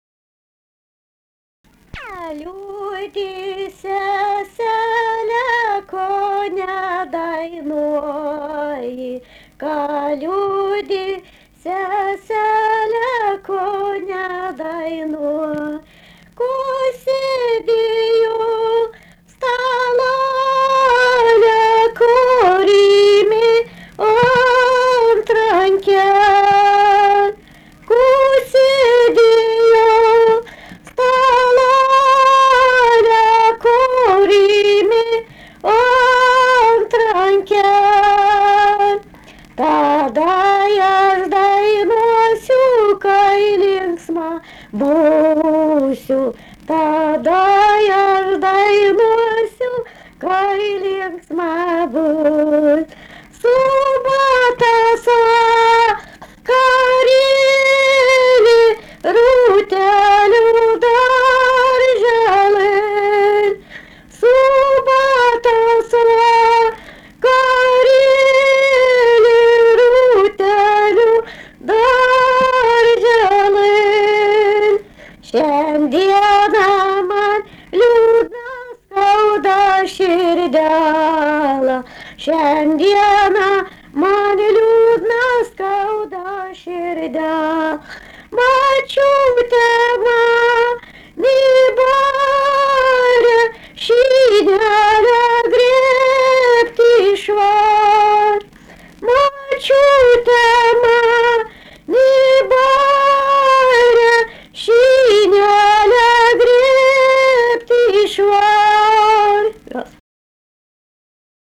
daina vestuvių Erdvinė aprėptis Jukoniai
Atlikimo pubūdis vokalinis